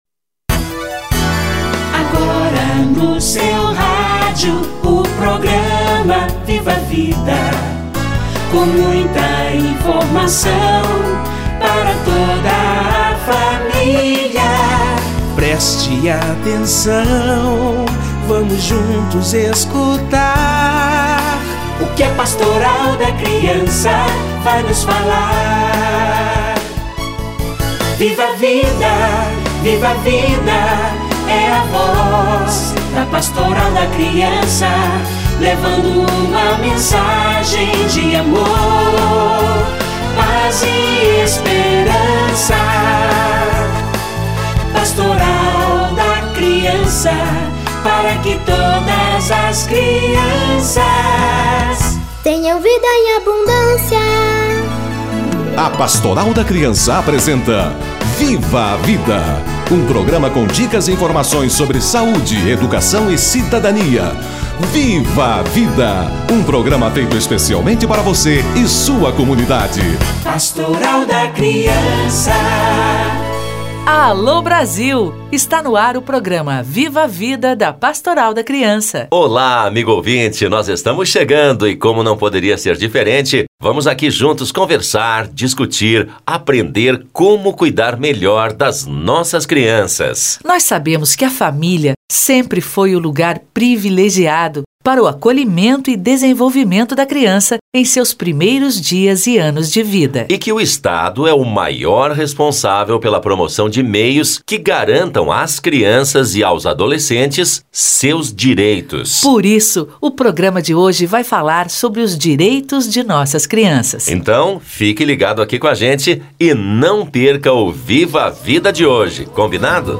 Direitos da criança - Entrevista